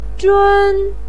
zhun1.mp3